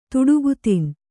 ♪ tuḍugu tin